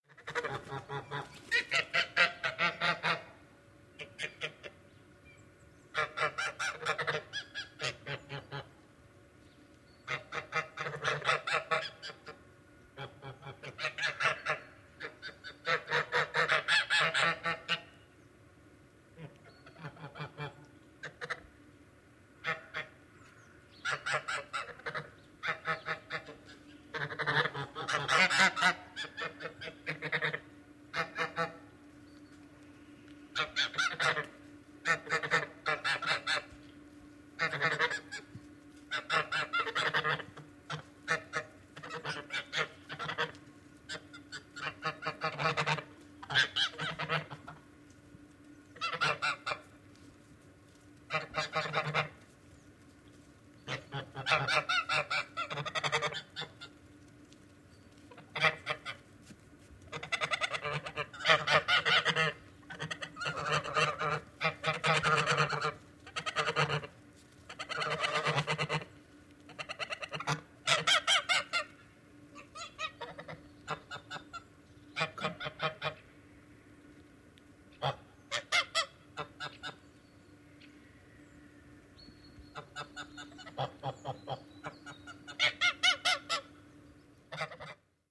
В подборке представлены природные звуки, медитативные мелодии и фоновые шумы для релаксации, работы и сна.
Гуууси